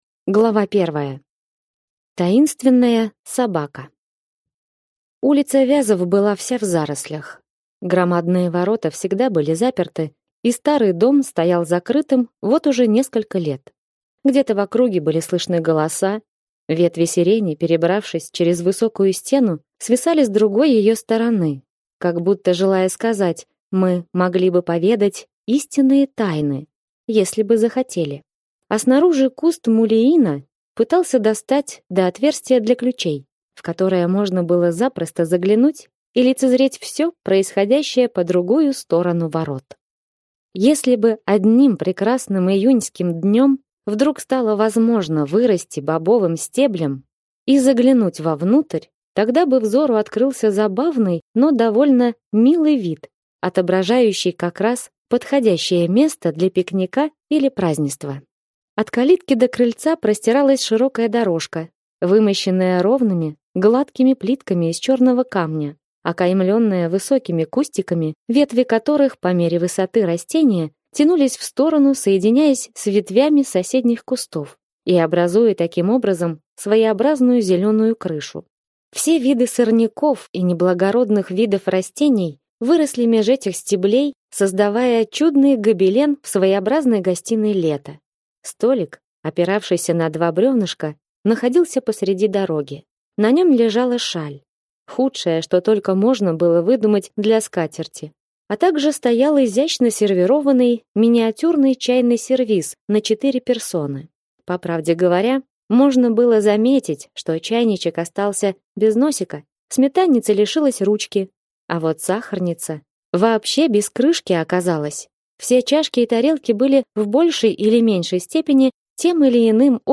Аудиокнига Под сиренями | Библиотека аудиокниг
Прослушать и бесплатно скачать фрагмент аудиокниги